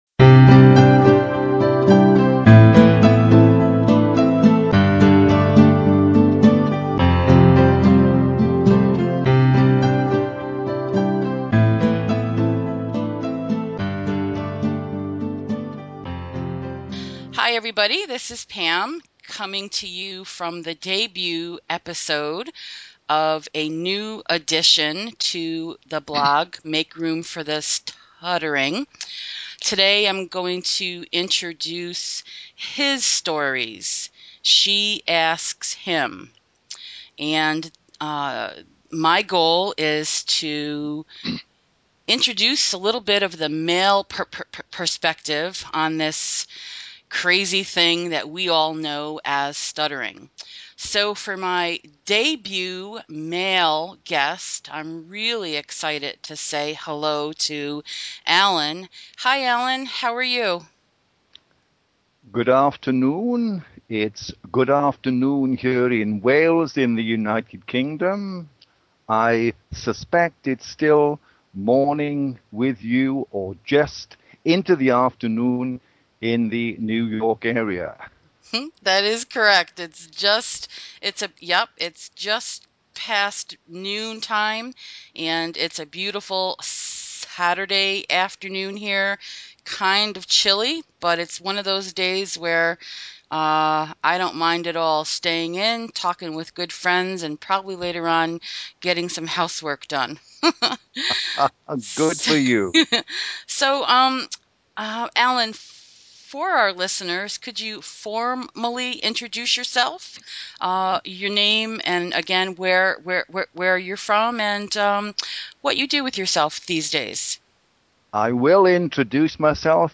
This inaugural episode of men who stutter sharing HIStories is a risk for me.